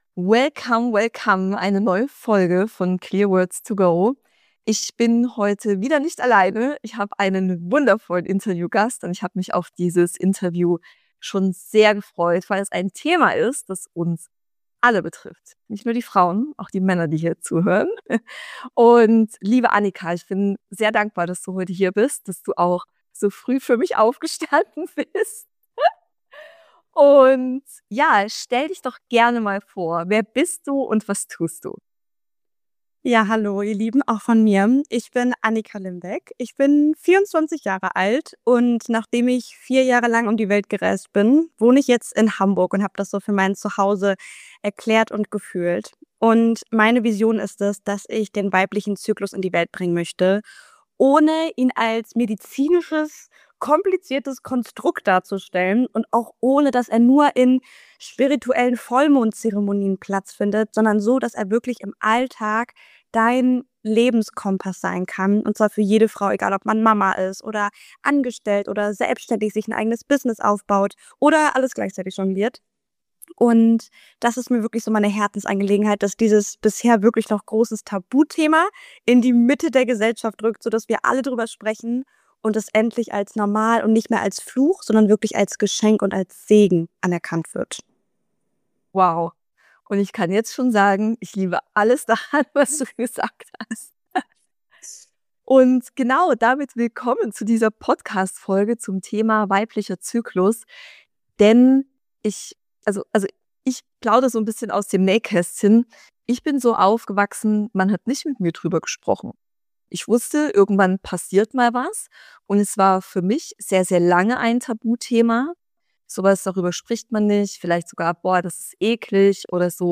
#96 Dein Zyklus als Business-Planer: Interview